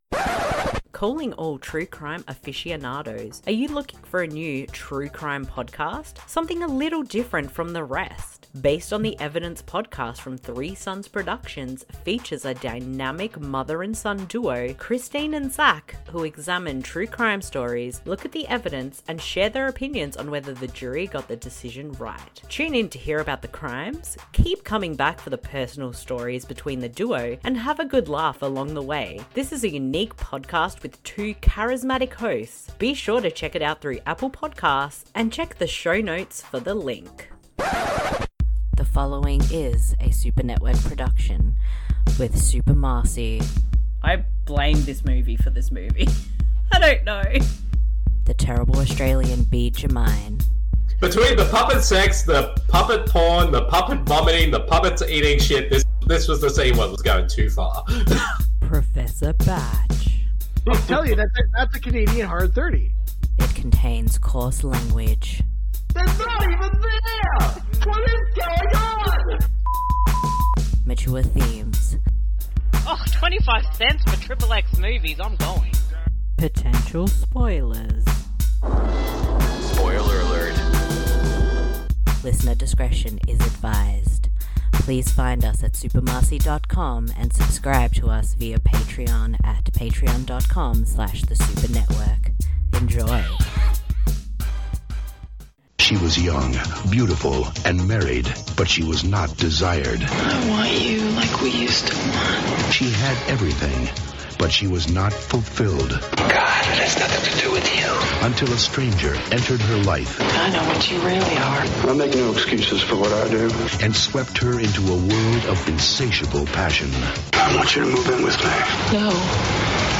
DISCLAIMER: This audio commentary isn’t meant to be taken seriously, it is just a humourous look at a film.